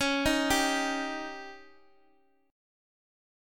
Dbsus2b5 chord